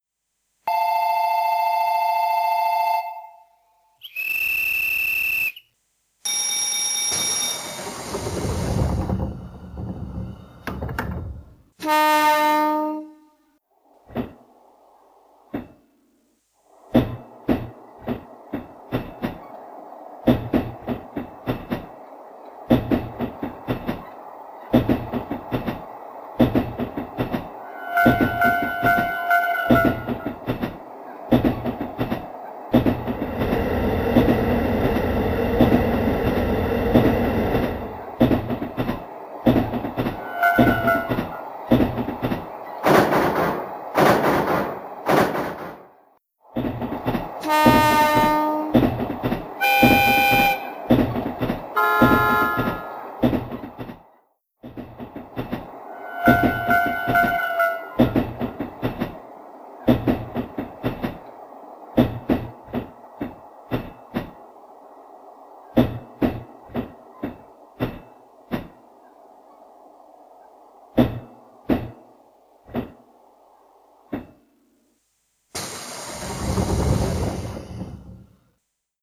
お手持ちの車両にレールジョイント音のほか、「発車ベル・手笛・踏切・鉄橋・トンネル」などの環境音を追加して、様々な走行シーンを演出可能です。
・転動音+ジョイント音のリズムを聞きながら、お好みの車両(電車・客車など)で運転士や乗客になりきって運転を楽しめます。
・6つのファンクションボタンを押すことにより、ドアや警笛などの車両の普遍的なサウンドや、トンネルや踏切、鉄橋の通過といった音で鉄道の旅を演出します。